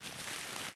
crumple4.ogg